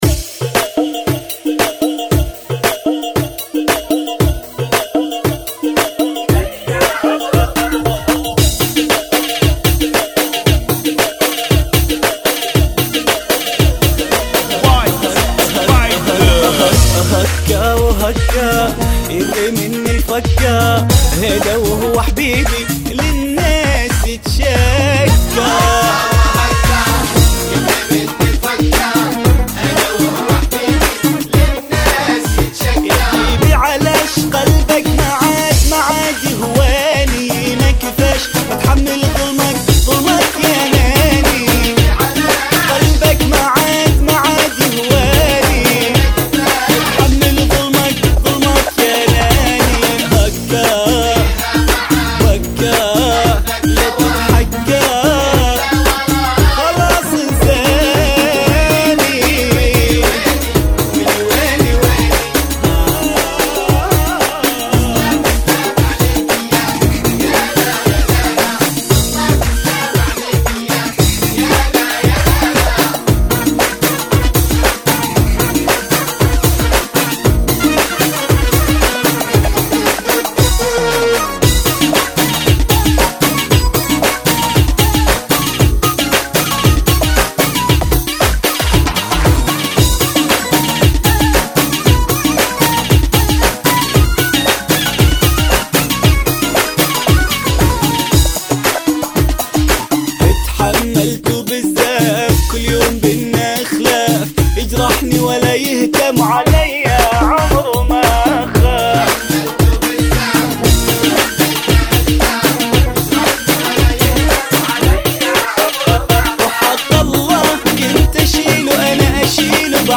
Funky [ 115 Bpm